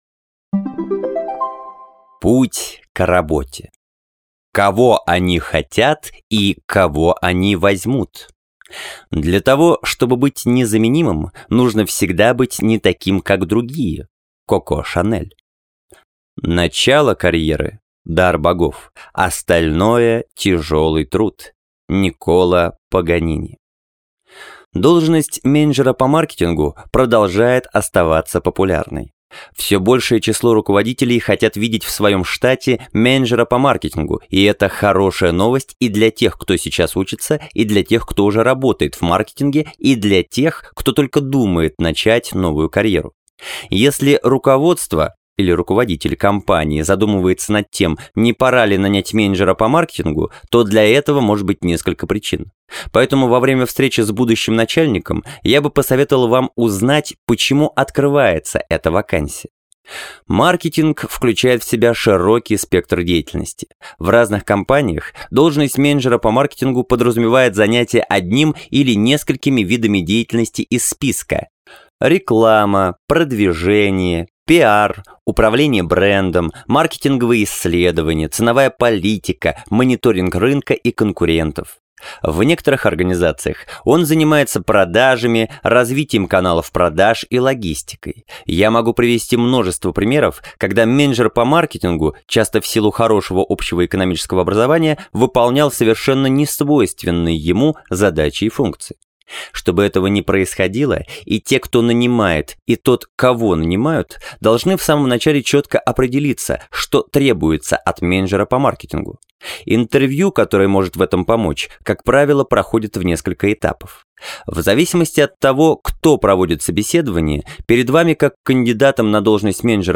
Бизнес-Аудиокниги
Аудиокниги